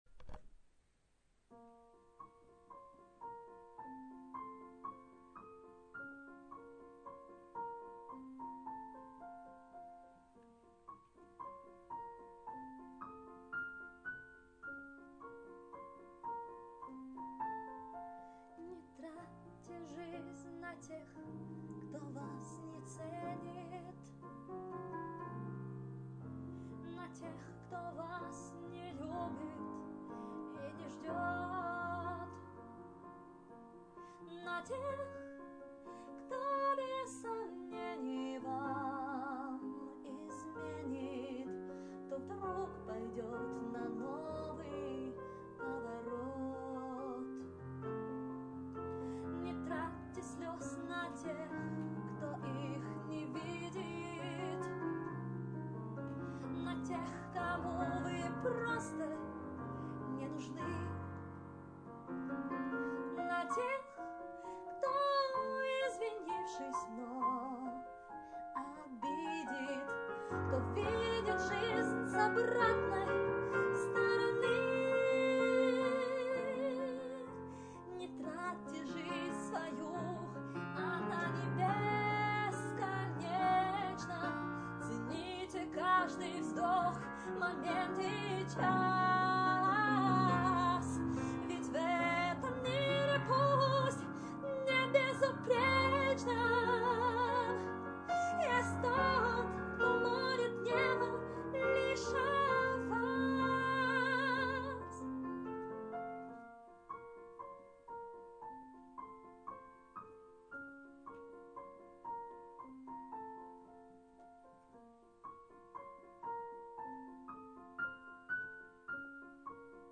Рубрика: Поезія, Авторська пісня
да, немного сыровато. но это даже придает произведению некое очарование.
Жаль, что качество записи плохое.